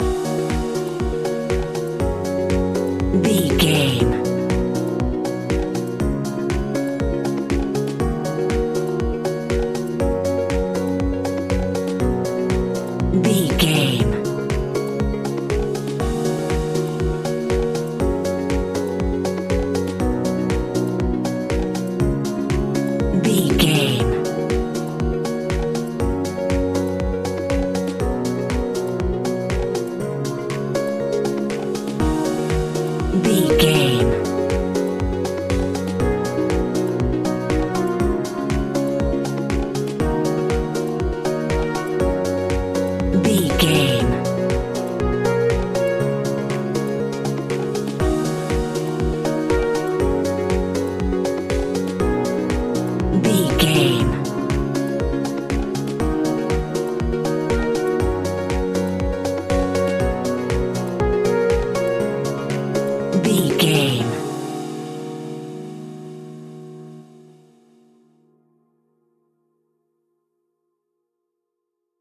Aeolian/Minor
E♭
groovy
uplifting
hypnotic
dreamy
smooth
piano
drum machine
synthesiser
electro house
funky house
synth leads
synth bass